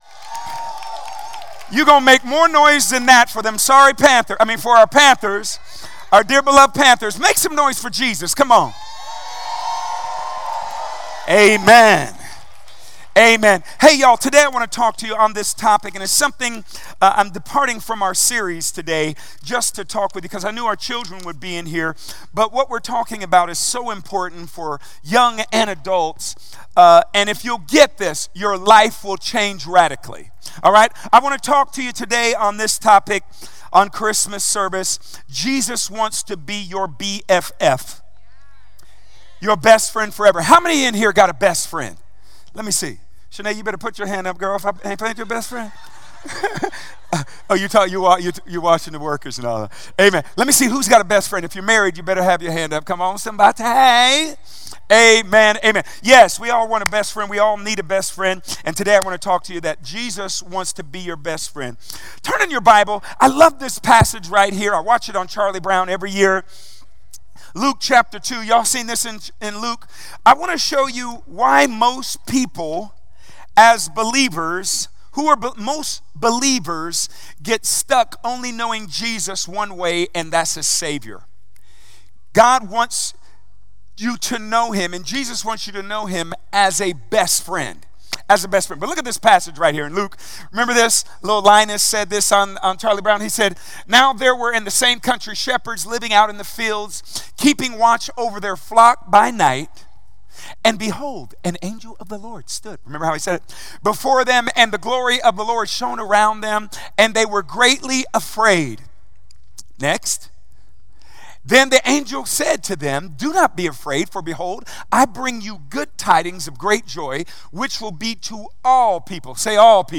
Christmas Service